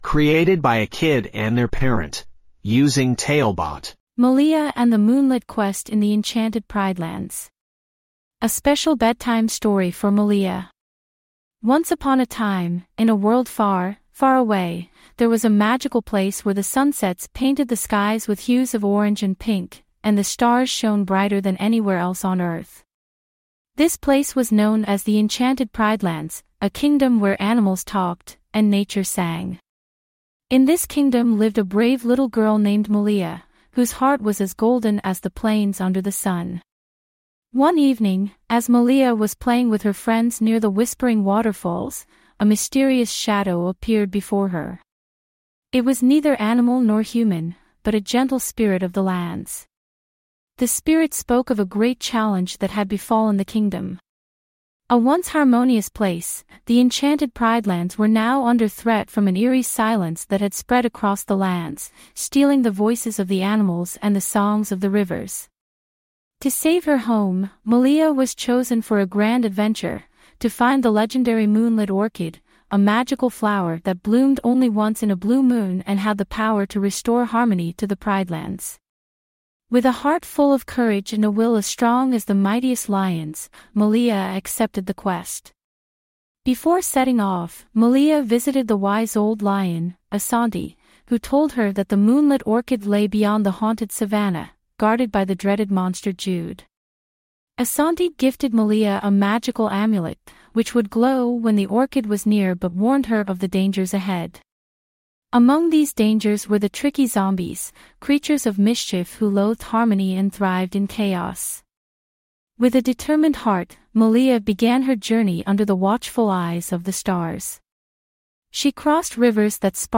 5 minute bedtime stories.